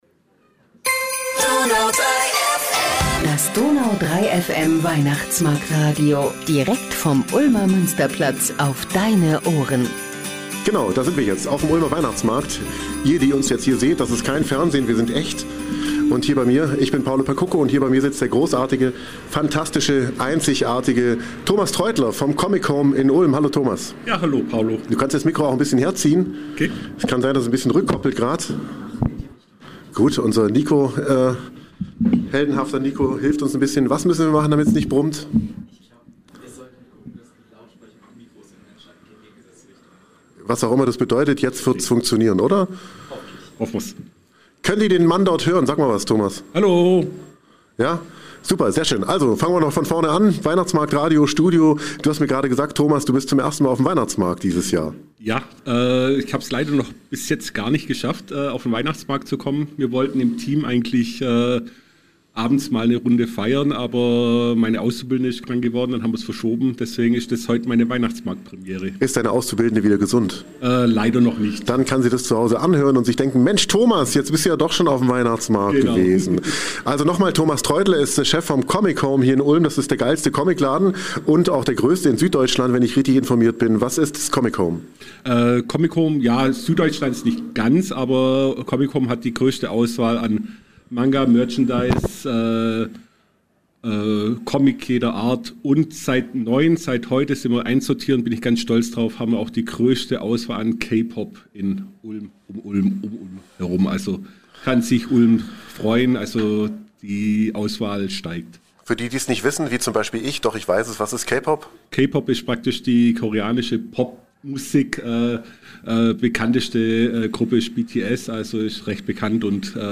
Direkt aus dem gläsernen Studio auf dem Ulmer Weihnachtsmarkt